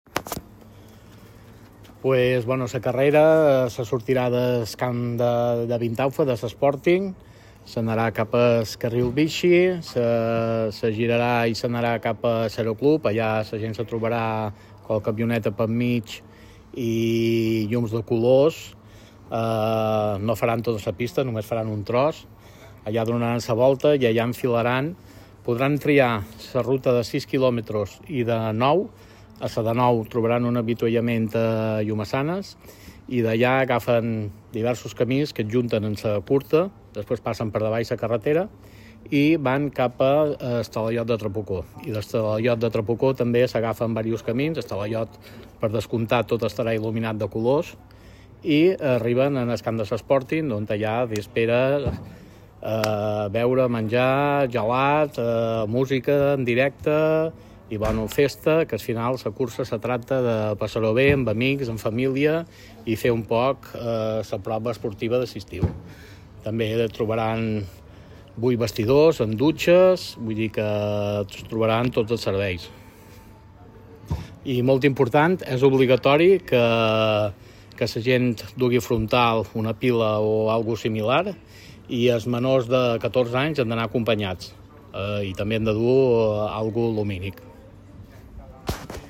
Declaraciones del Menorca Atletisme